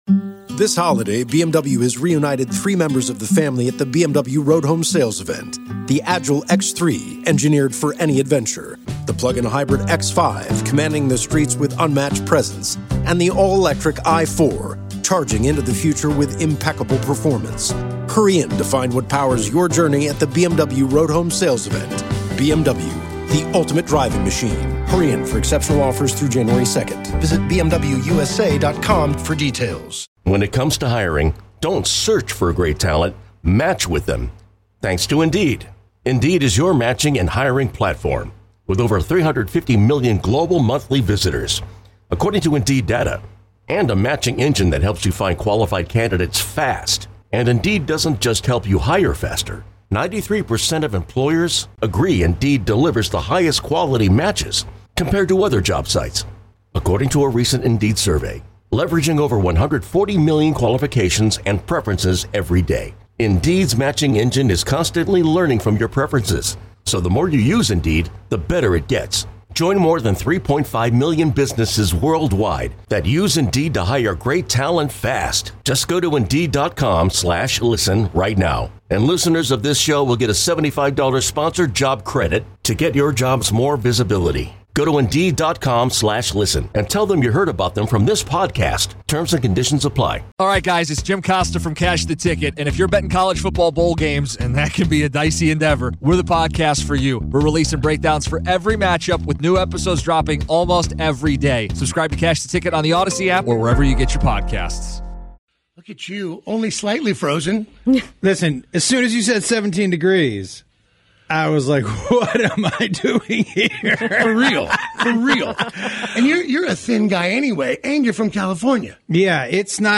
We spend the morning with comedian/friend of the show JOSH WOLF!!
Any day that our old friend, comedian Josh Wolf, rolls into KC and stops by the studio is a good day!